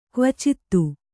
♪ kvacittu